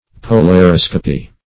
Definition of polariscopy.